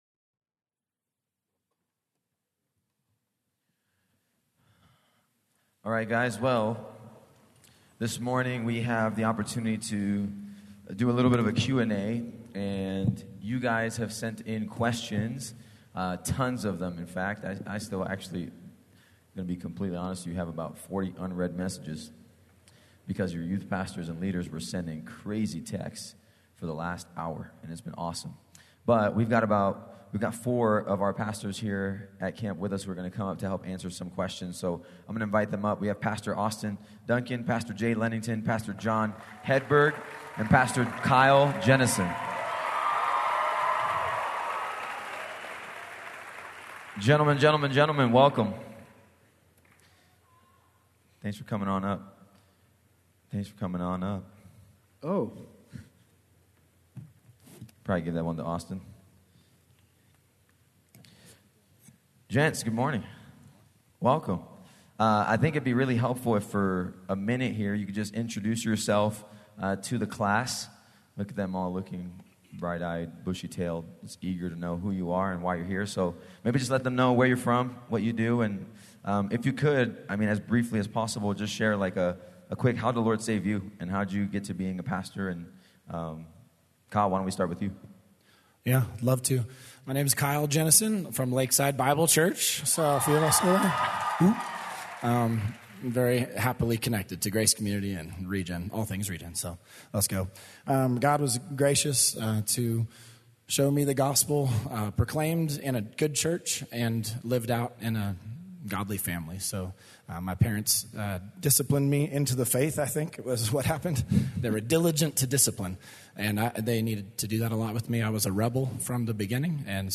Session 6: Youth Pastor Q&A